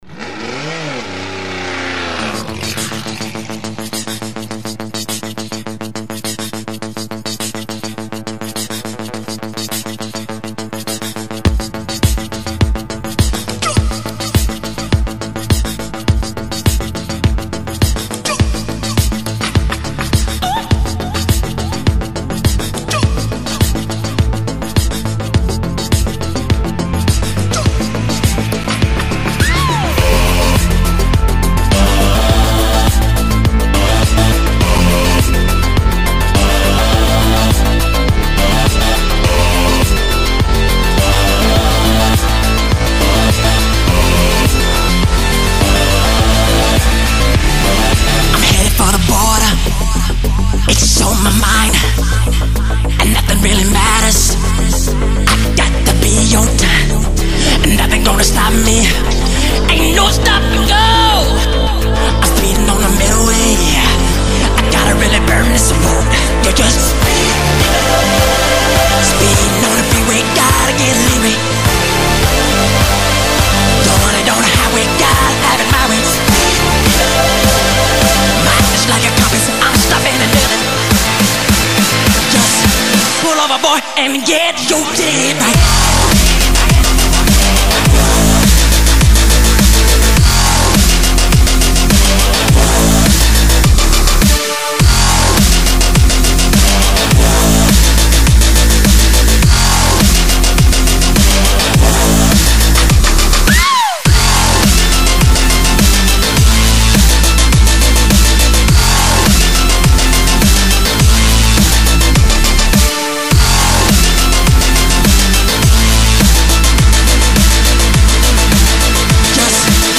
Главная » Dab Step